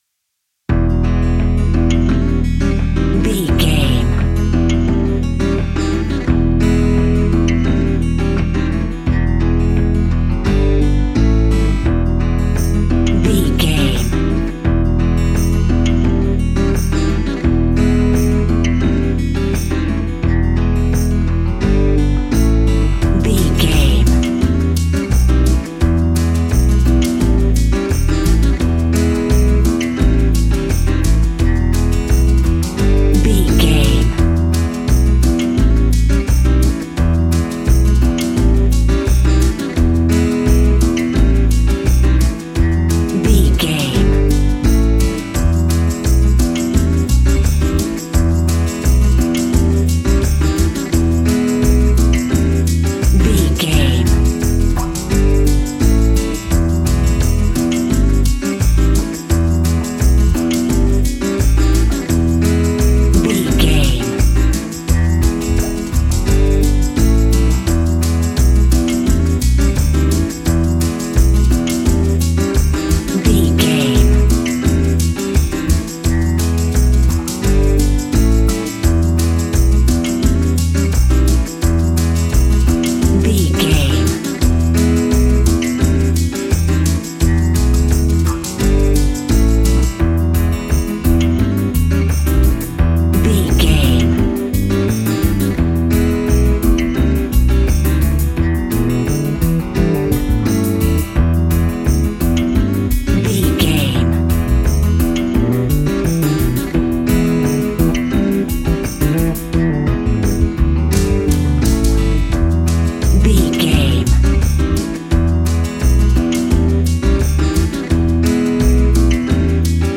Aeolian/Minor
romantic
sweet
happy
acoustic guitar
bass guitar
drums